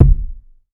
TC Kick 29.wav